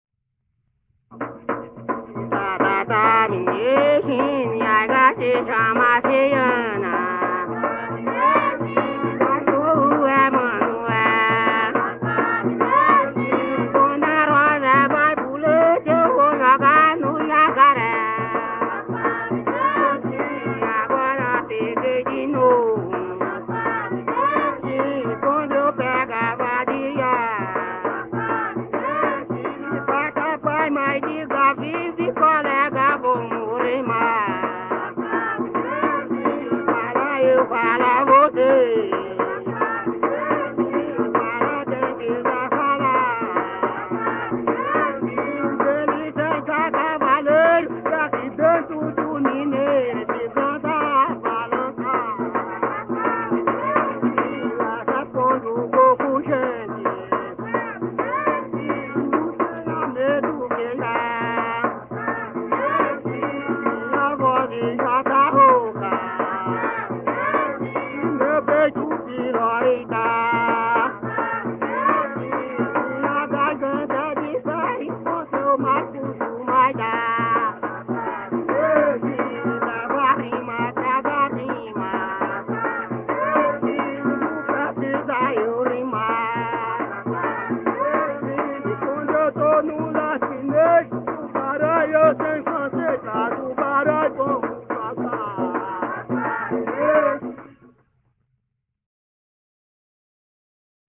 Coco embolada -""Tatata, mineiro china""